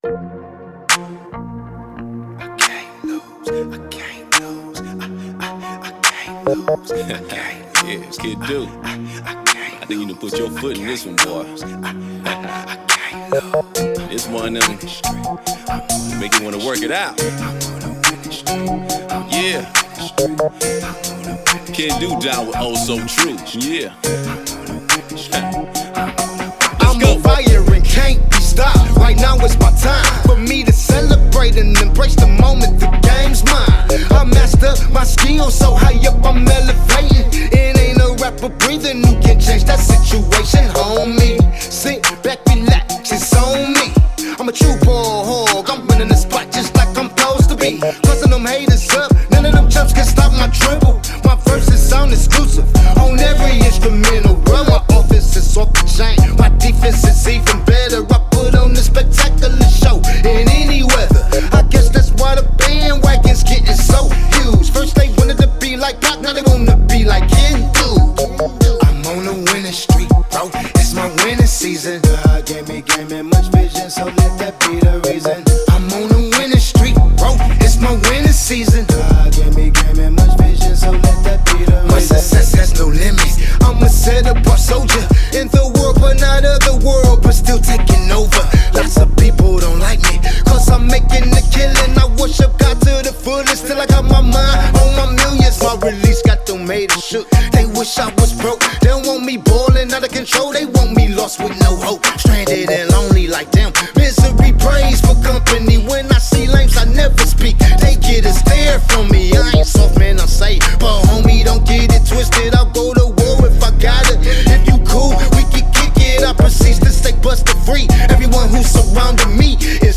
Christian/Gospel
Description: This is a dope track with powerful dope lyrics